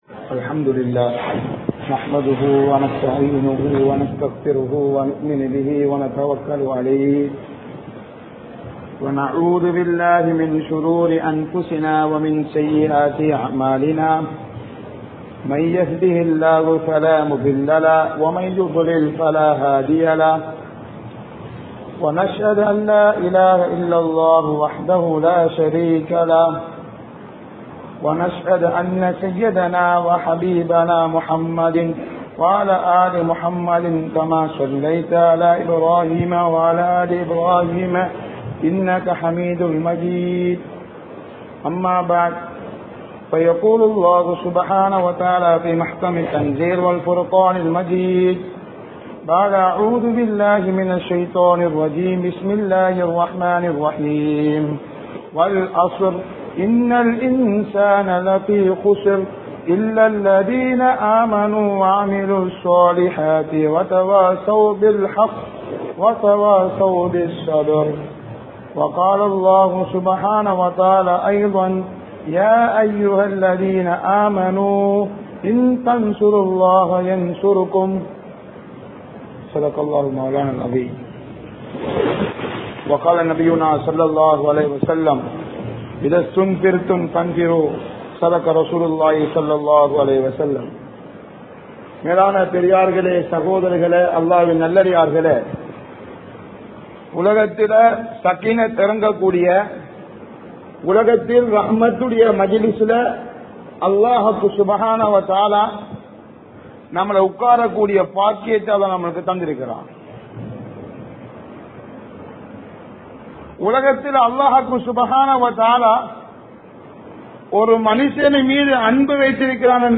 Deenukku Uthavi Seiythaal Allah Uthavi Seivaan(தீனுக்கு உதவி செய்தால் அல்லாஹ் உதவி செய்வான்) | Audio Bayans | All Ceylon Muslim Youth Community | Addalaichenai